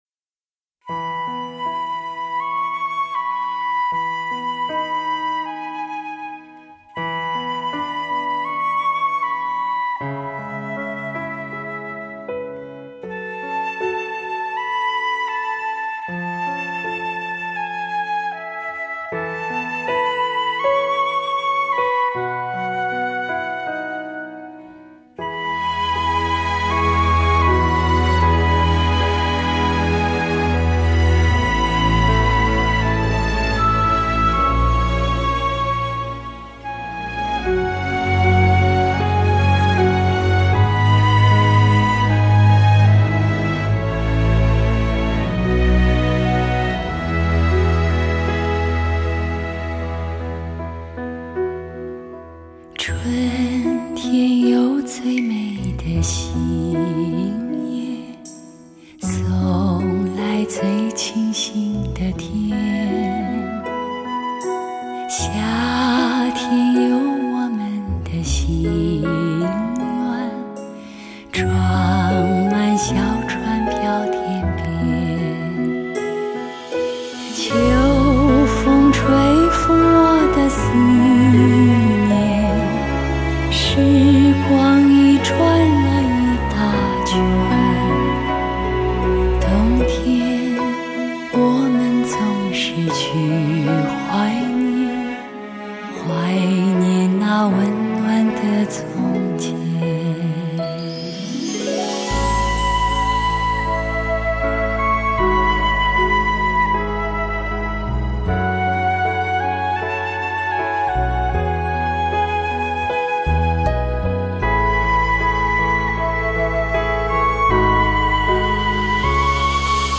唱片类型：华语流行
富有磁性的女中音